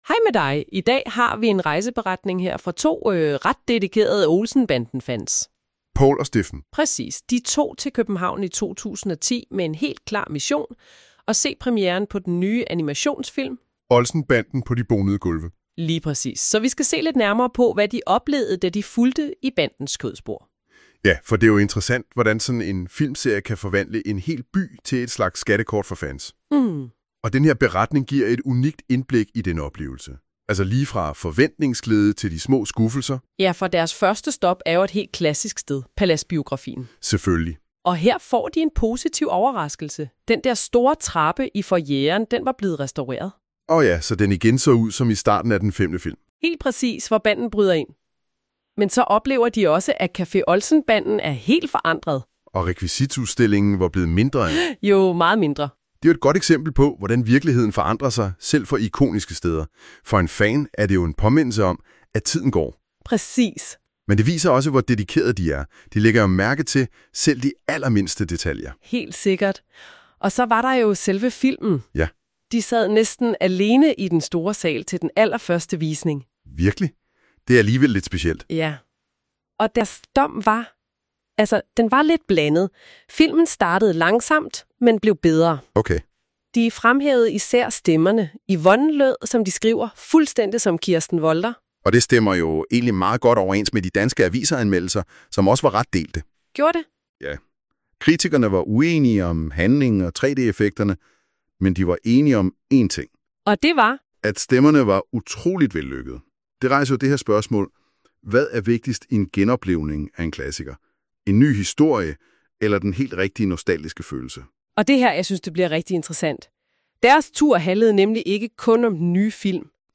Lydresumé i podcastformat
MP3 (AI-genereret lydindhold)